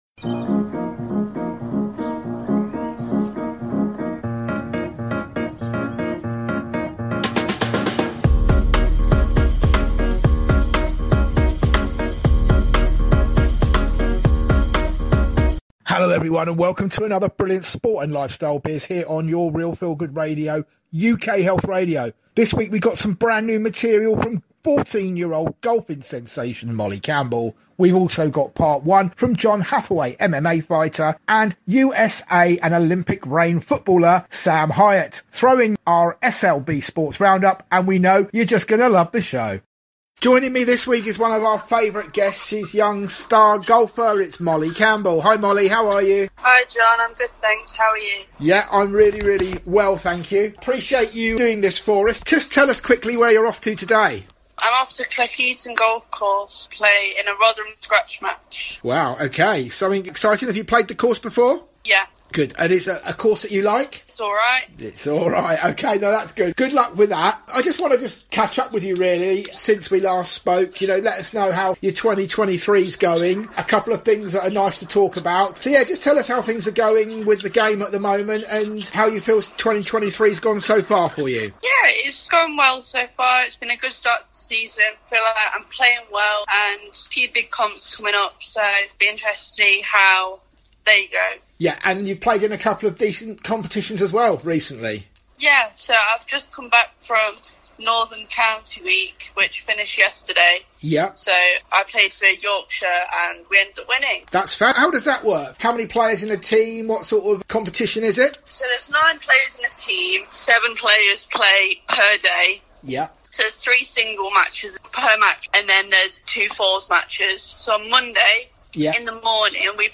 Sports and Lifestyle Biz is a fast, informative and action-packed show bringing you all from the world of sport with a hint of lifestyle and biz.
We will bring you the best from all the major sporting news and events including Football, Formula 1, Rugby and Cricket to the extreme sports like Chess Boxing. We will bring you features on youth foundations and the development areas for youngsters plus interviews with inspirational women and children.